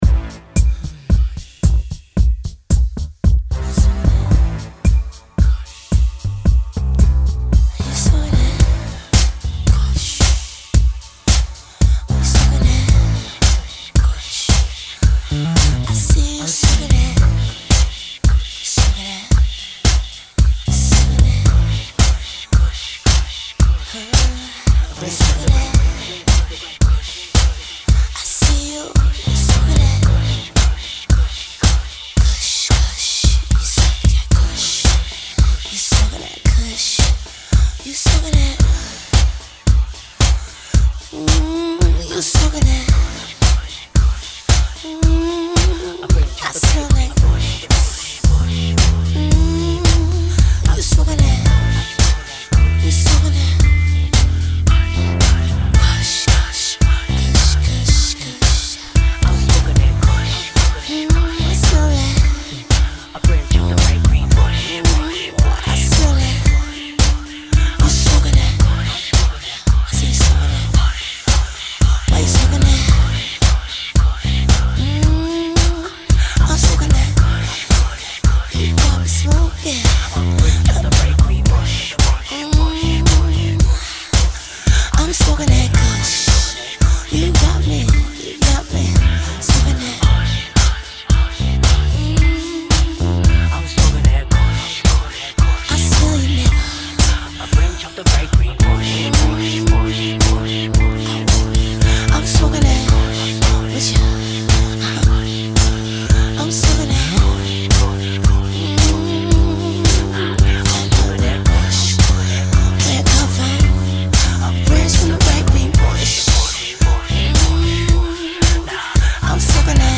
Hiphop
extra dope uptempo dance track